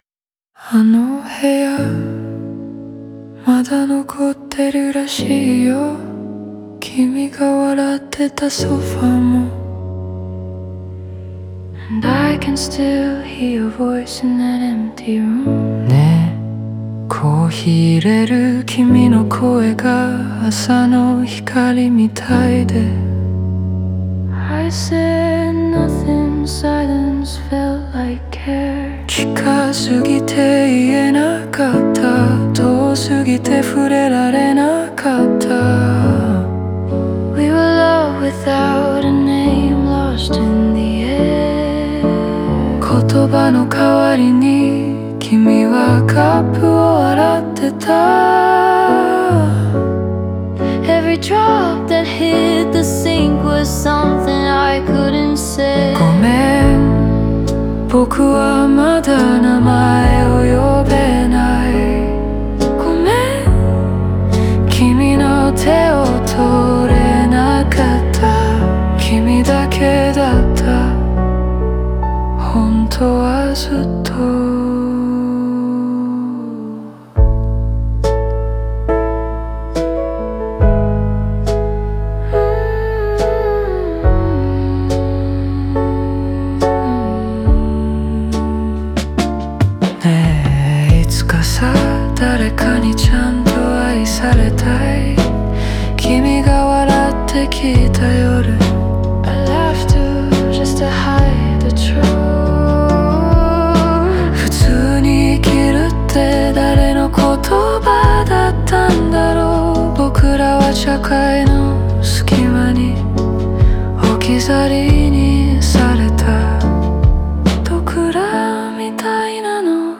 ピアノ主体の旋律に合わせ、静かな切なさと温かみが同居する情感豊かな歌詞である。